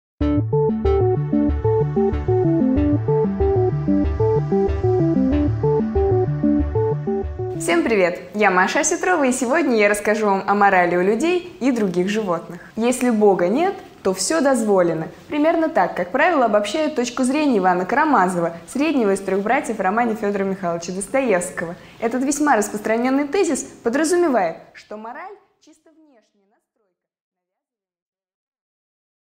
Аудиокнига 5 минут О морали у приматов